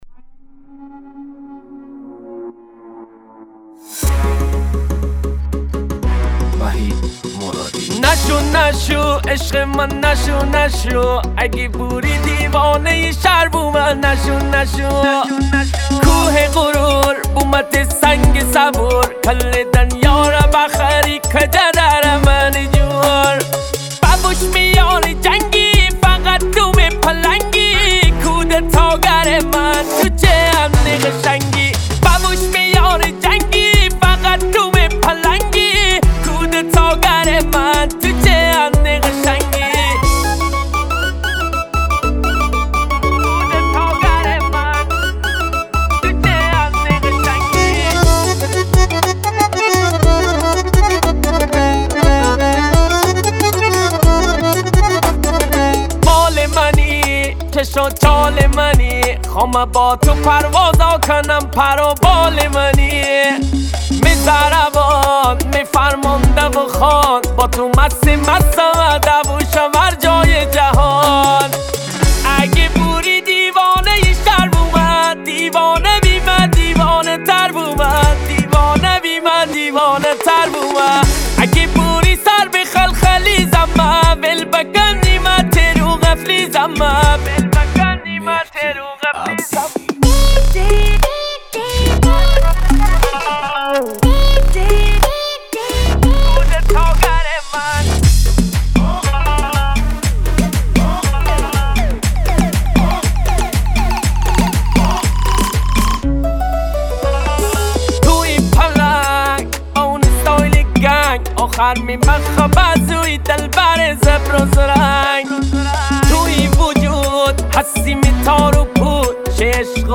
ریمیکس مازندرانی اینستا